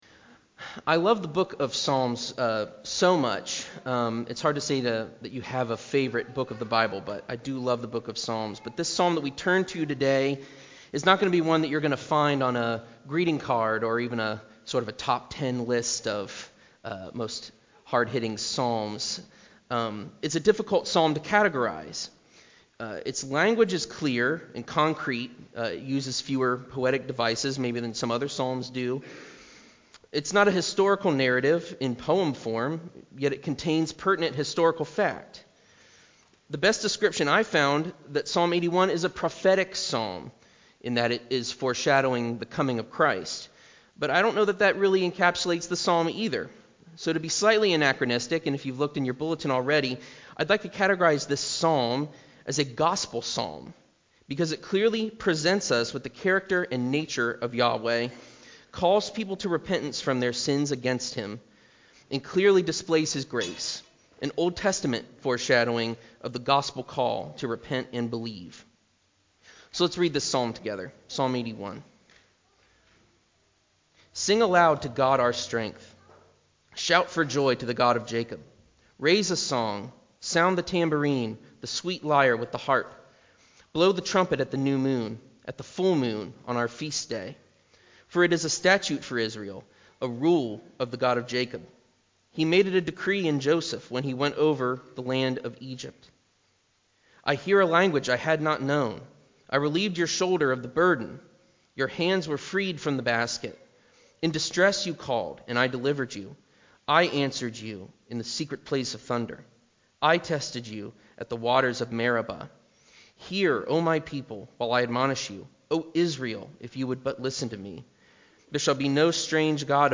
3.27.22-sermon-CD.mp3